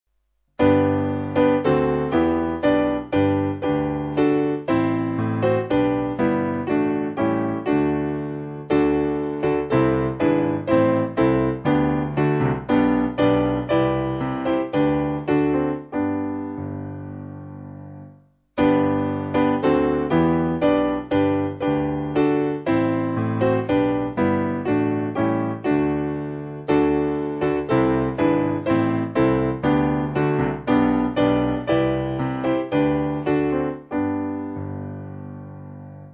Simple Piano
(CM)   6/G